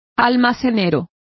Complete with pronunciation of the translation of grocer.